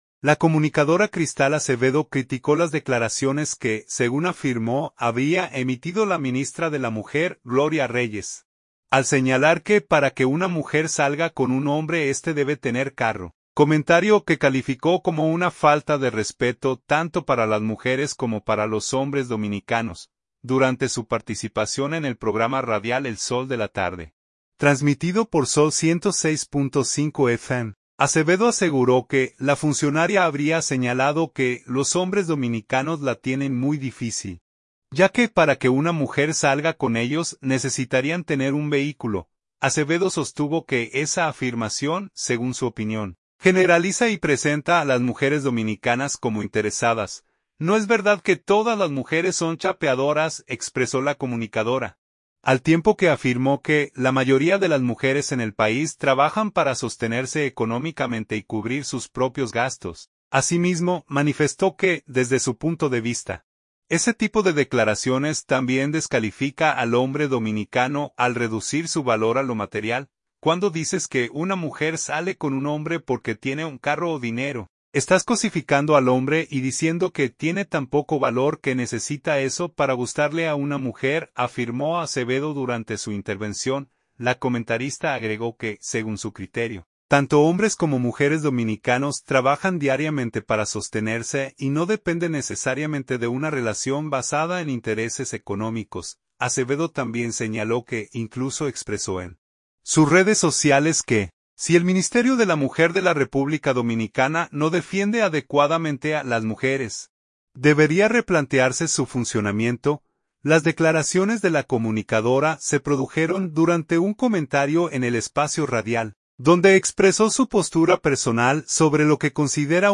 Las declaraciones de la comunicadora se produjeron durante un comentario en el espacio radial, donde expresó su postura personal sobre lo que considera un mensaje equivocado hacia la sociedad dominicana.